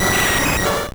Cri de Nosferalto dans Pokémon Or et Argent.